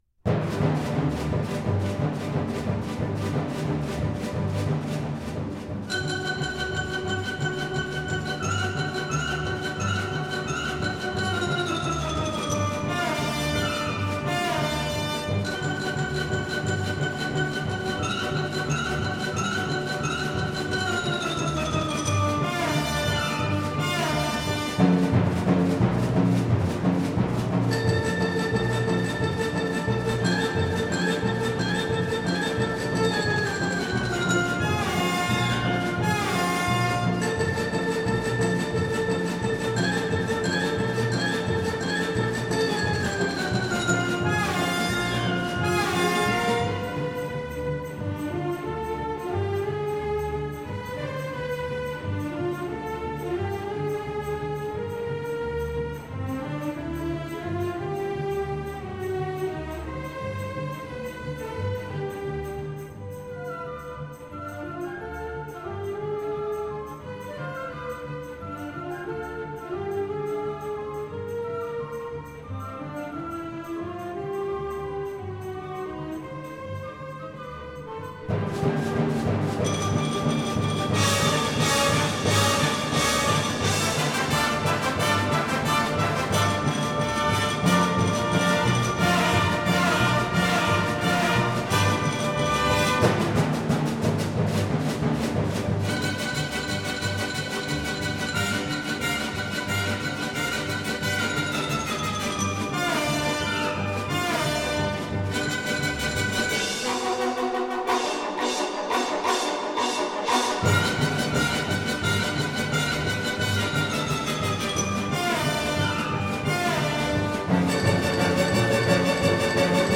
名家名作，可以听到各国、各派的不同风格与精神。